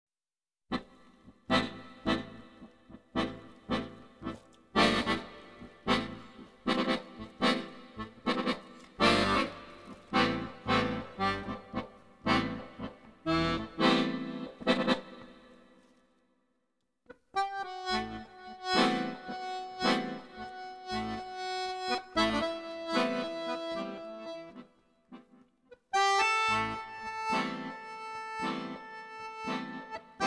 In this CD you find a completly improvised performance.